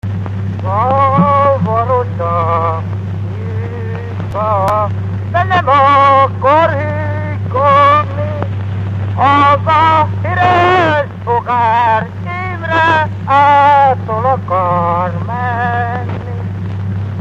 Alföld - Pest-Pilis-Solt-Kiskun vm. - Kecskemét
Stílus: 8. Újszerű kisambitusú dallamok
Kadencia: VII (b3) 4 1